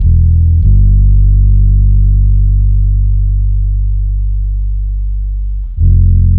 threenote2.mp3